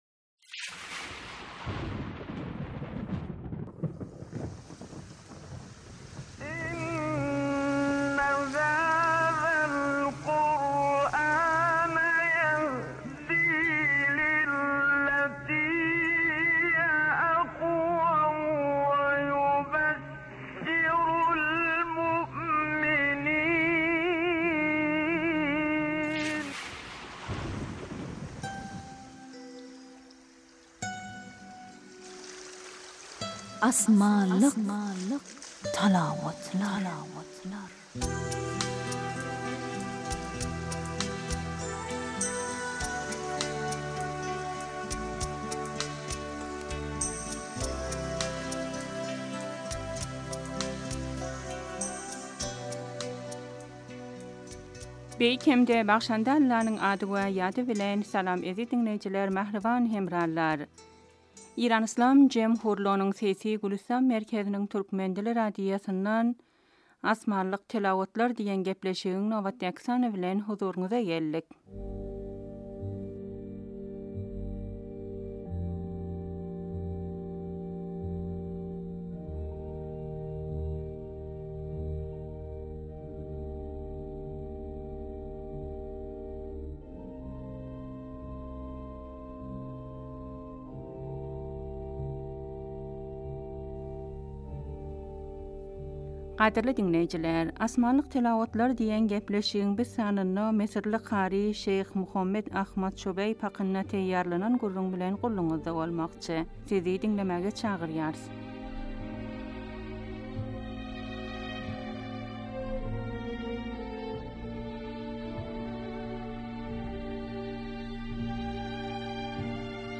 Asmanlik talawatlar